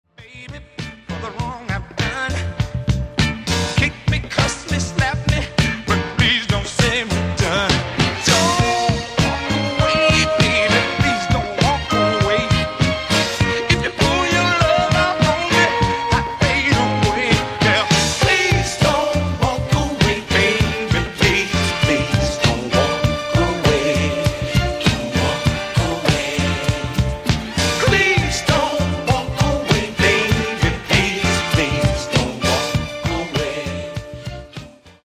Genere: Disco | Soul |